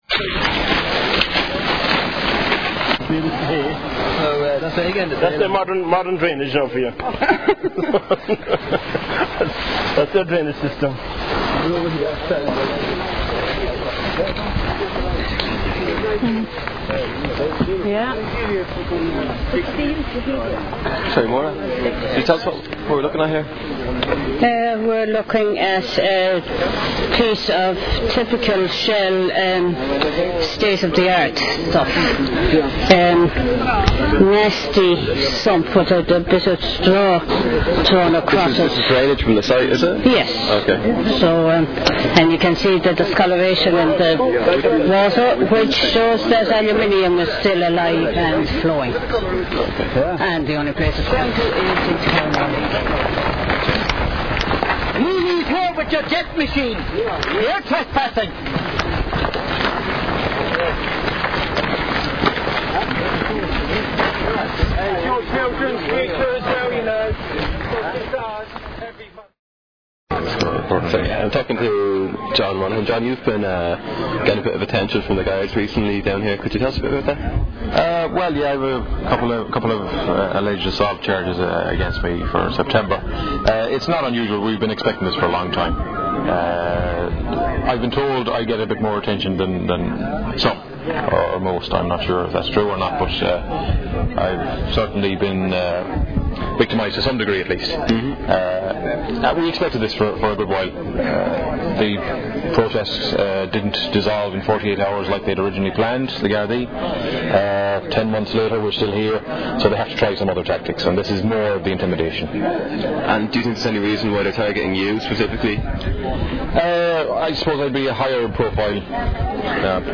Interviews with Mayo locals
Audio recorded before, during and after the mass trespass on the refinery site. Ends with sounds of the jubilant 'trespassers' exiting through the main gate. Loud booms can be heard as people drummed on the fence in celebration.